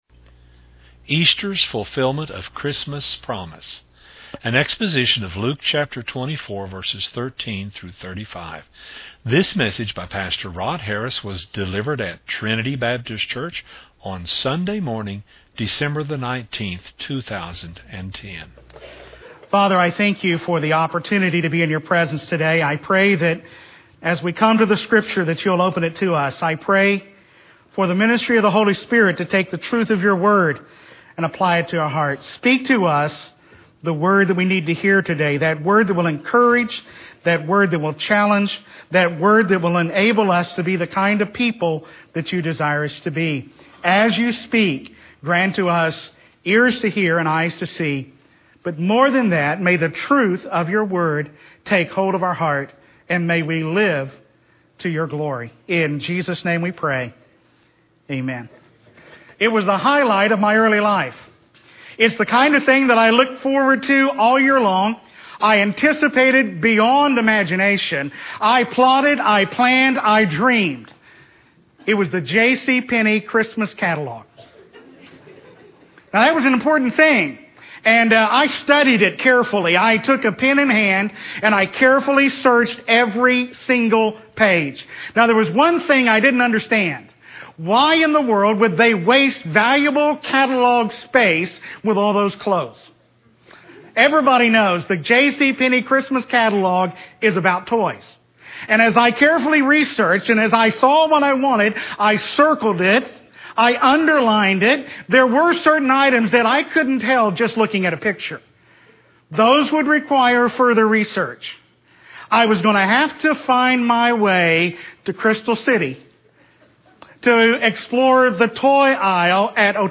An exposition of Luke 24:13-35.